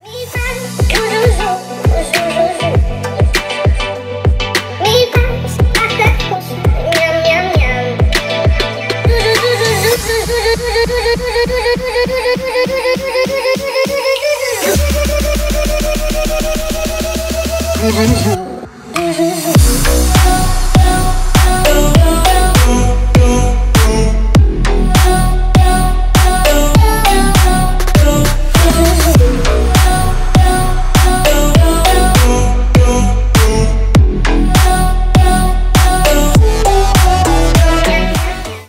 Ремикс
Поп Музыка
весёлые